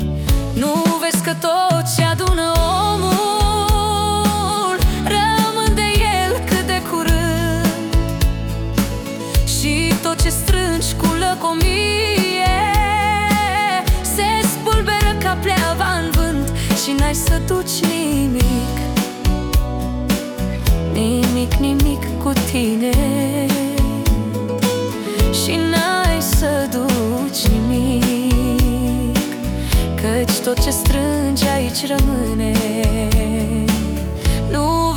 Жанр: Фолк